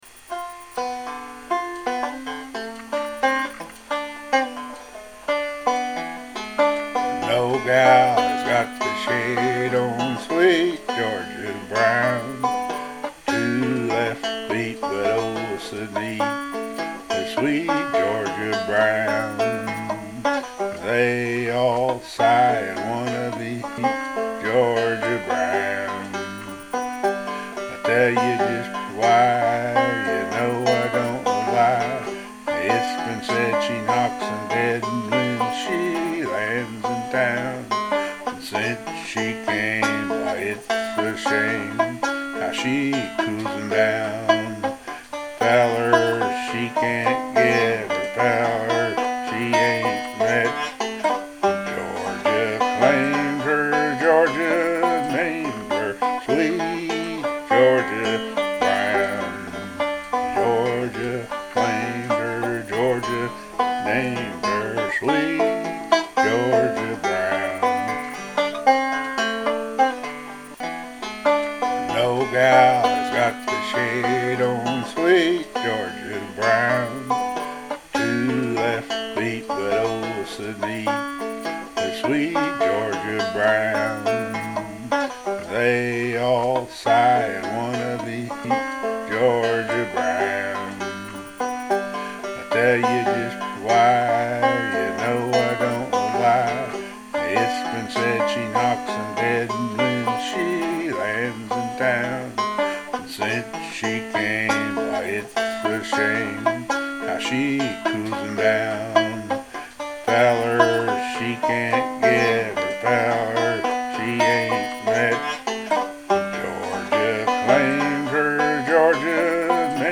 Swing Time (1925-1955)